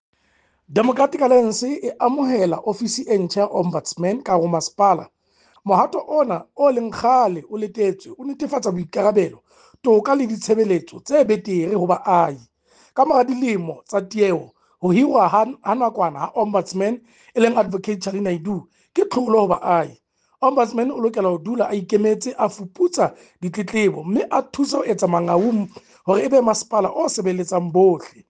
Sesotho soundbite by Cllr Kabelo Moreeng.